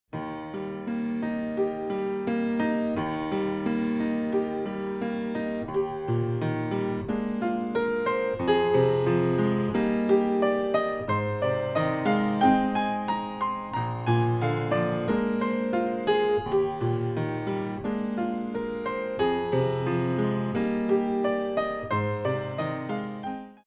Moderato
for Port de Bras